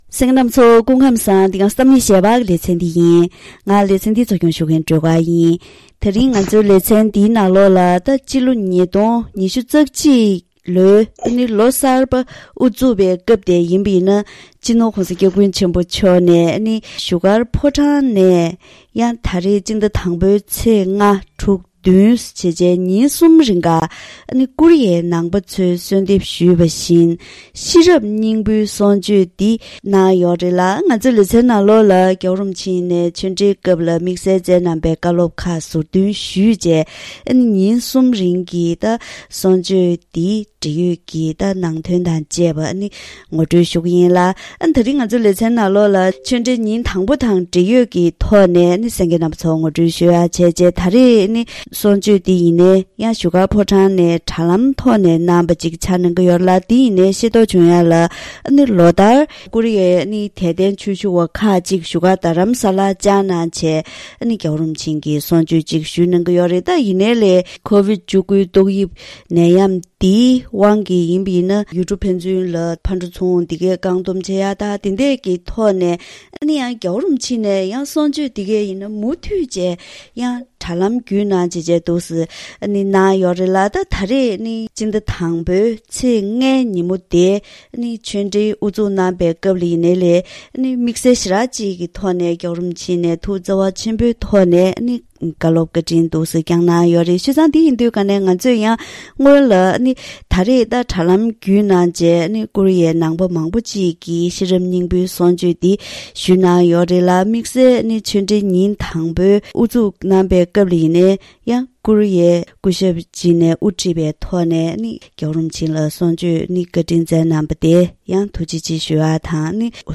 ད་རིང་གི་གཏམ་གླེང་ཞལ་པར་ལེ་ཚན་ནང་ཀོ་རི་ཡའི་ནང་པ་ཚོས་གསོལ་འདེབས་ཞུས་པ་བཞིན་སྤྱི་ནོར་༧གོང་ས་༧སྐྱབས་མགོན་ཆེན་པོ་མཆོག་གིས་བཞུགས་སྒར་ཕོ་བྲང་ནས་དྲ་ལམ་བརྒྱུད་ཤེས་རབ་སྙིང་པོའི་གསུང་ཆོས་གནང་ཡོད་པ་དང་། བཀའ་ཆོས་ཉིན་དང་པོའི་སྐབས་སངས་རྒྱས་ཀྱི་ཆོས་ལ་དད་པ་གཅིག་པུ་མ་ཡིན་པར་གཙོ་བོ་ཤེས་རབ་ཡིན་སྐོར་དང་། རྗེས་ལ་ཀོ་རི་ཡའི་དད་ལྡན་པས་༧གོང་ས་མཆོག་ལ་བཀའ་འདྲི་ཞུས་པར་བཀའ་ལན་གནང་པ་སོགས་ཀྱི་ཞིབ་ཕྲའི་གནས་ཚུལ་ཁག་ངོ་སྤྲོད་ཞུས་པ་ཞིག་གསན་རོགས་གནང་།